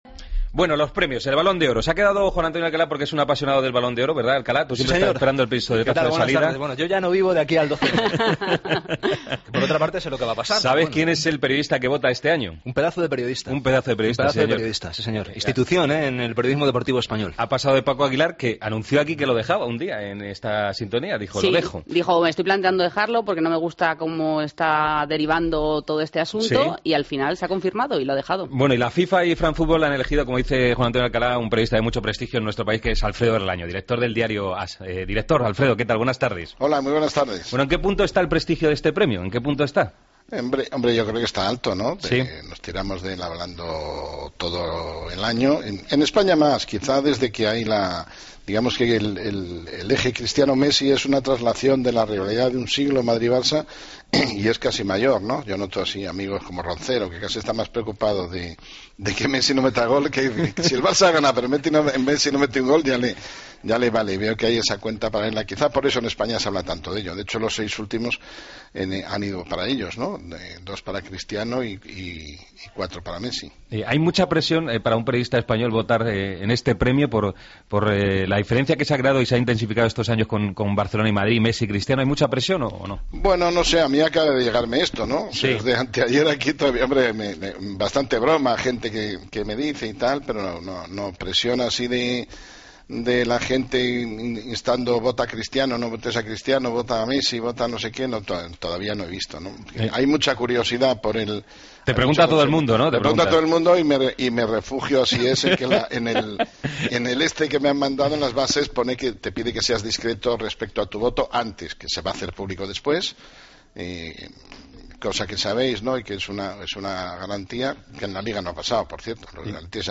Alfredo Relaño, periodista español que vota en el Balón de Oro, explicó en Deportes COPE en qué consisten las votaciones.